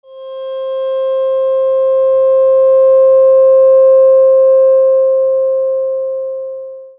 528Hz Aluminium Tuning Fork
Handcrafted 528Hz Unweighted Tuning Fork made from high-grade aluminium, providing a long and enduring tone.
The handcrafted Ragg 528Hz Aluminium Tuning Fork is made from the highest quality aluminium to ensure long and enduring vibrations. 528 Hz is one of the Solfeggio frequencies and is suggested to have several uses, one being that it is the frequency of love, and another is the frequency for DNA repair.
528Hz-Tuning-Fork.mp3